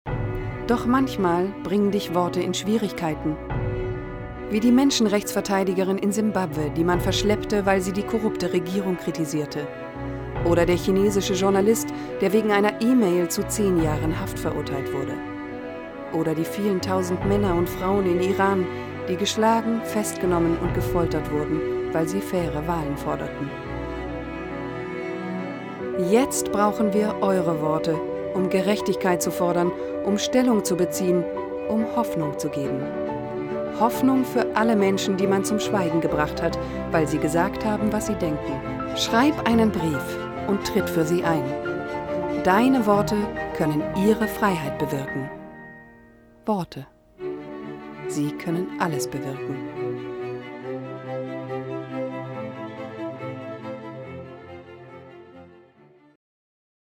Commercial (Werbung), Narrative, Off, Presentation